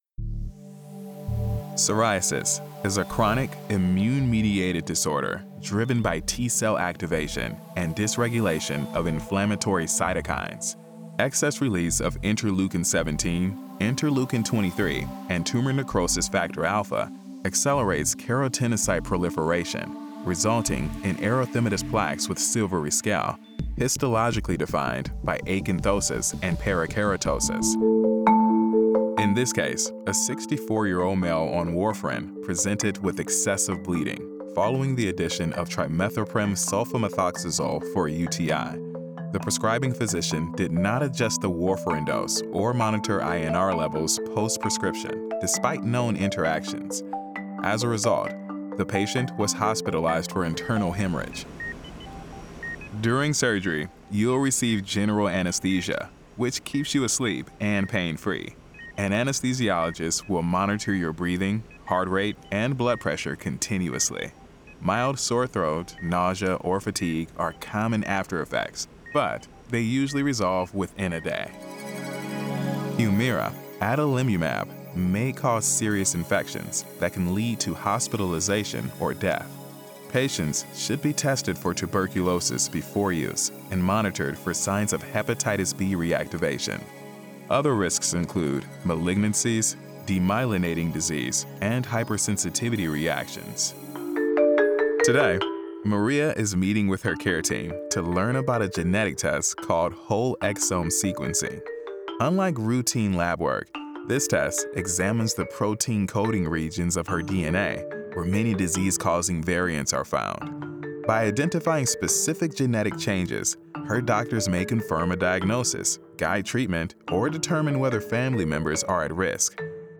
Medical Narration - Knowledgeable, Informed, Authoritative
From his home studio he narrates voiceovers with an articulate, genuine, conversational, and authentic feel.